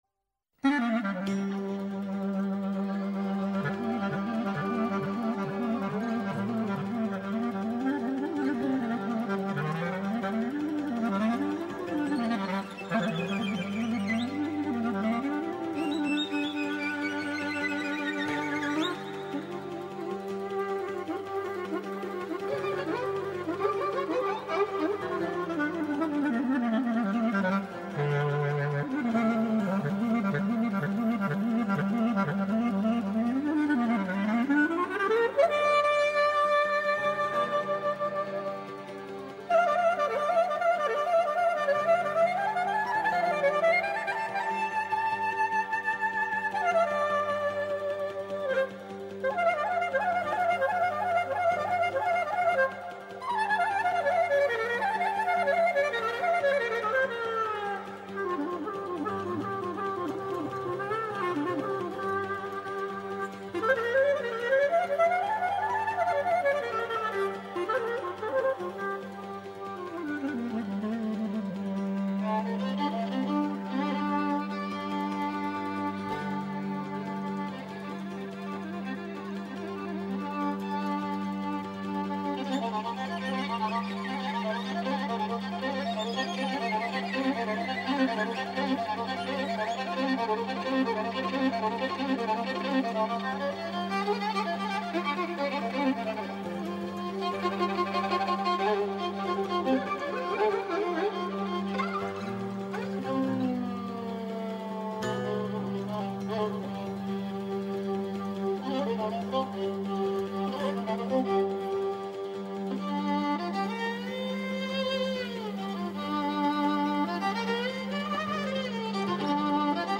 Ένα καθημερινό μουσικό ταξίδι στην παράδοση της Ελλάδας. Παλιές ηχογραφήσεις από το αρχείο της Ελληνικής Ραδιοφωνίας, νέες κυκλοφορίες δίσκων καθώς και νέες ηχογραφήσεις στο Στούντιο των Μουσικών Συνόλων της ΕΡΤ.